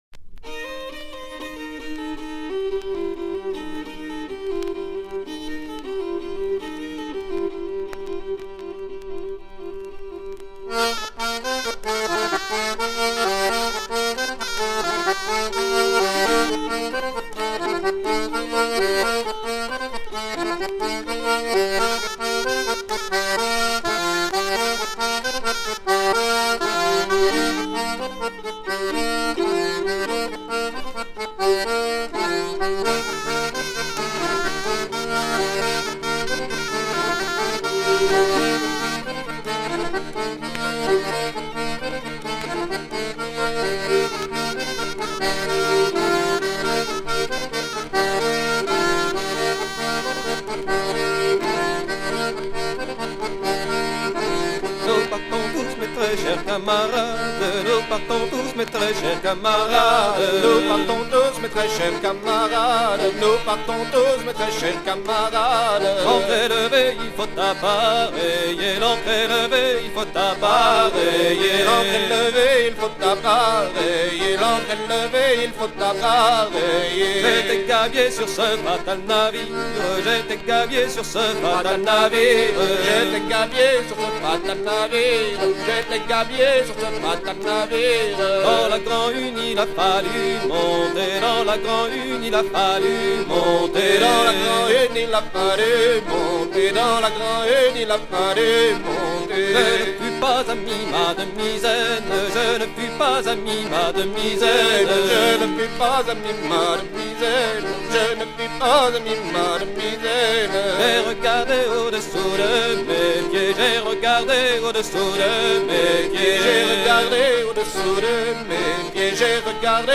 danse : ronde : rond de l'Île d'Yeu
Genre strophique
Pièce musicale éditée